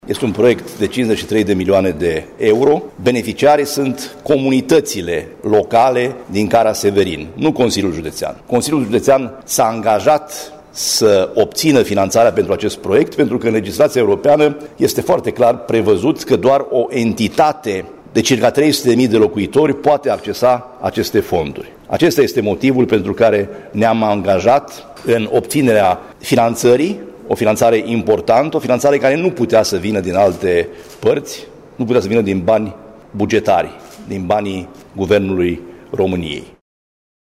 Ascultaţi declaraţia lui Sorin Frunzăverde despre acest important proiect: